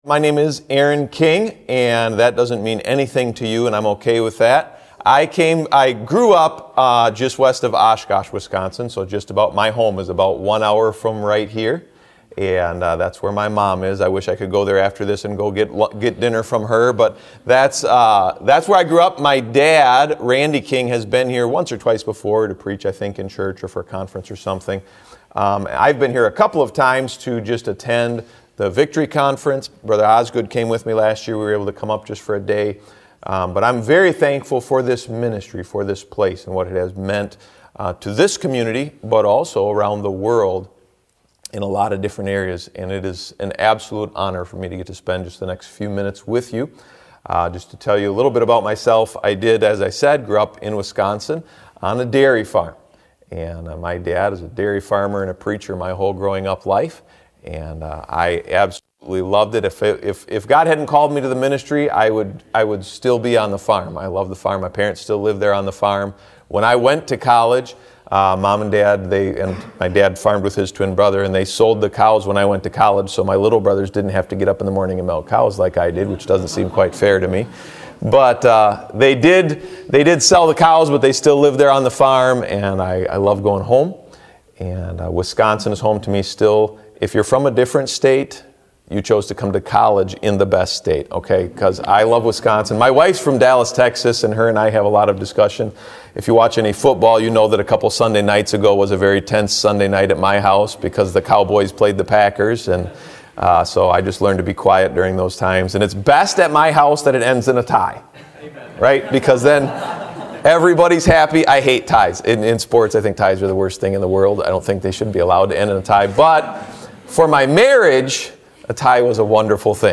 Preaching from the chapel platform of Baptist College of Ministry.